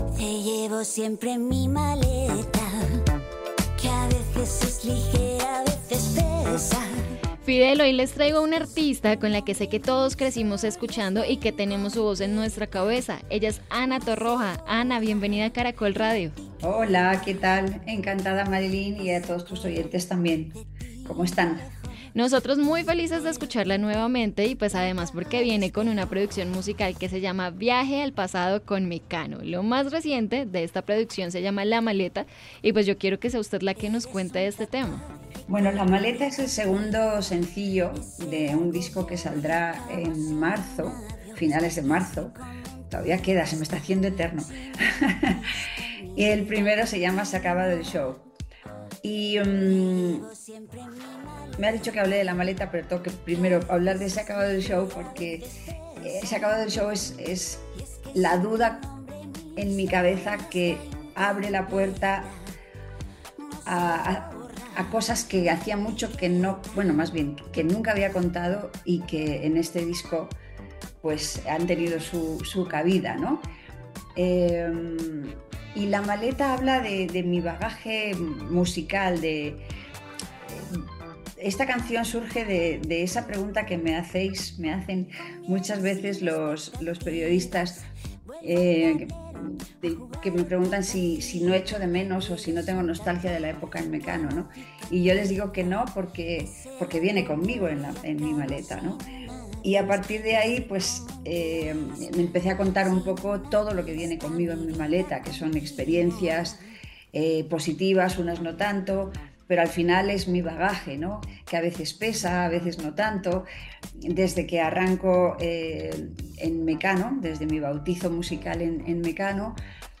Actualmente está promocionando su segundo corte llamado ‘La maleta’ y pasó por los micrófonos de Caracol Radio para hablar de esta canción, su nuevo disco y los proyectos que vienen para 2026.